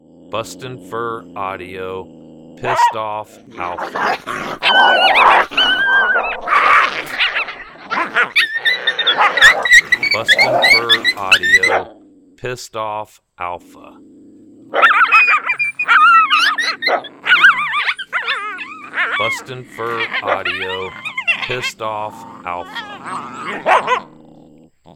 Very aggressive Coyote fight.
• Product Code: pups and fights